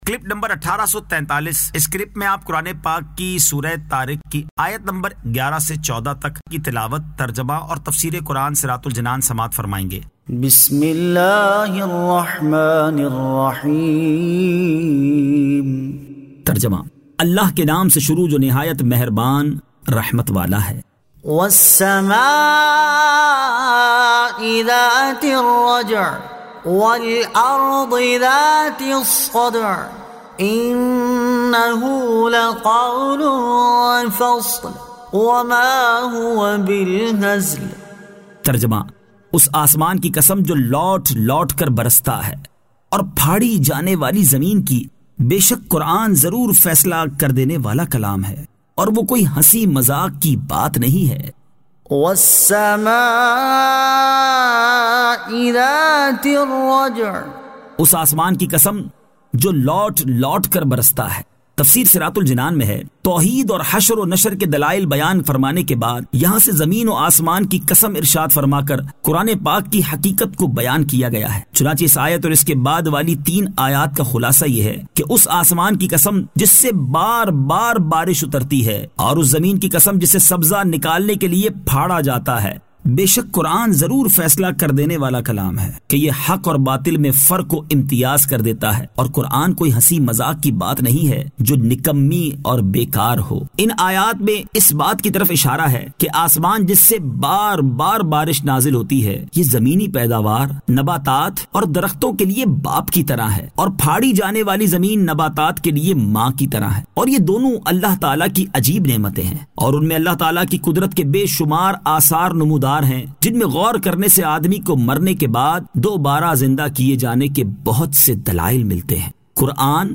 Surah At-Tariq 11 To 14 Tilawat , Tarjama , Tafseer